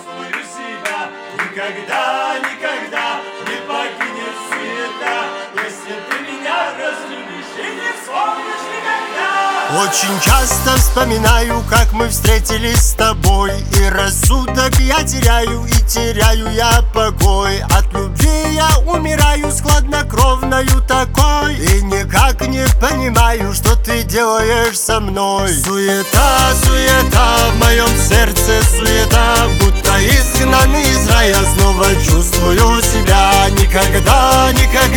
Жанр: Шансон / Русские
# Russian Chanson